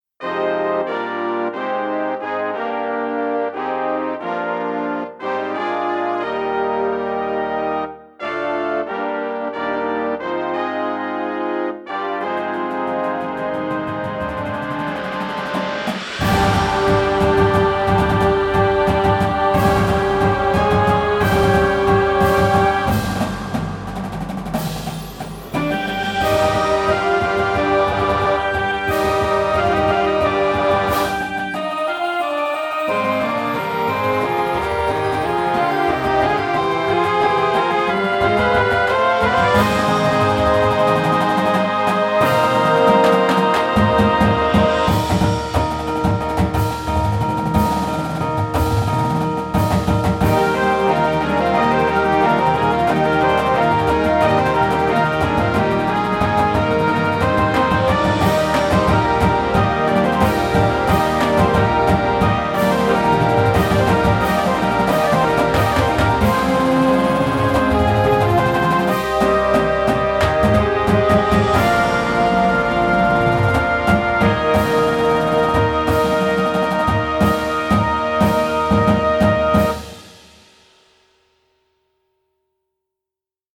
Instrumentation: Winds and Full Percussion